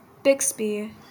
Bixby (/ˈbɪksbi/